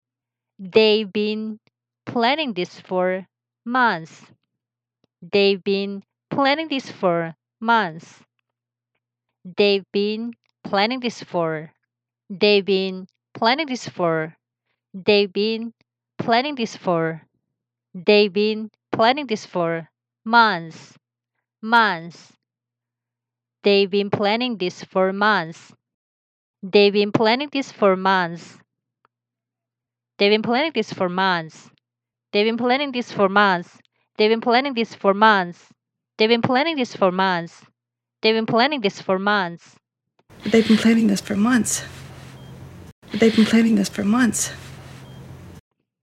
그러면 먼저 저와 함께 이렇게 천천히 시작해서 조금씩 빠르게 연습해 보아요.